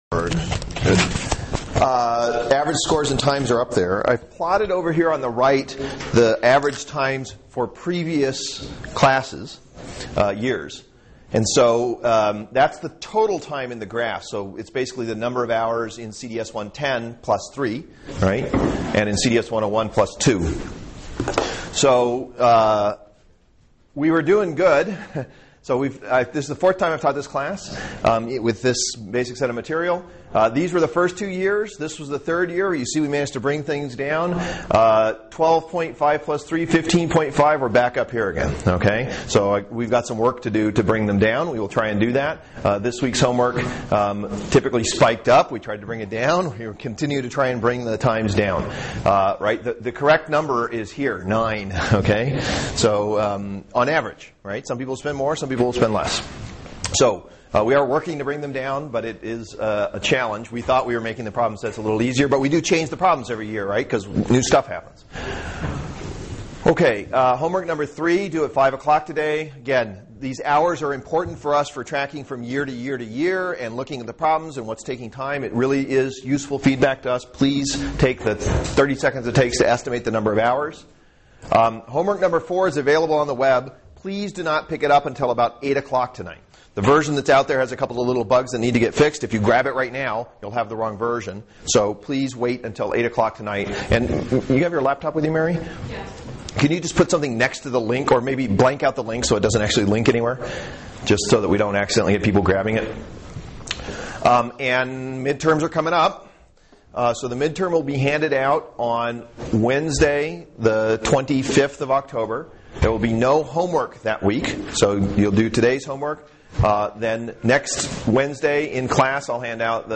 This lecture gives an introduction to linear input/output systems. The main properties of linear systems are given and the matrix exponential is used to provide a formula for the output response given an initial condition and input signal. Linearization of nonlinear systems as an approximation of the dynamics is also introduced.